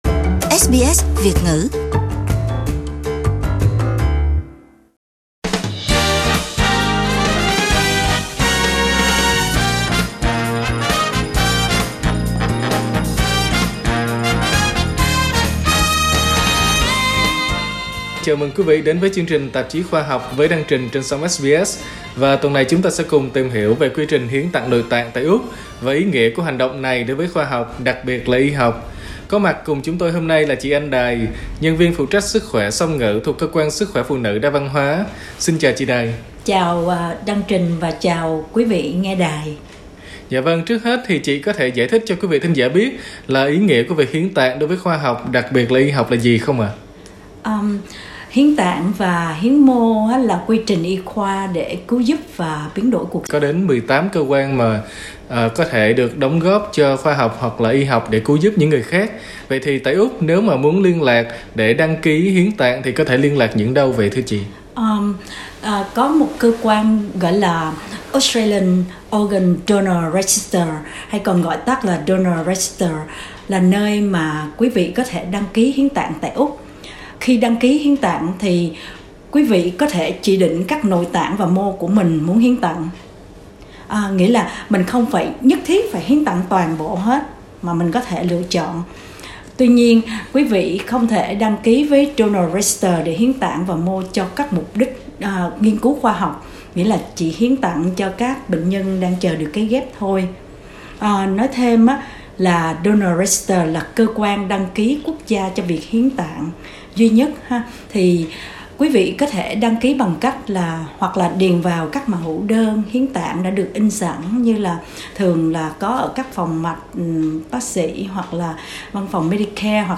SBS Vietnamese phỏng vấn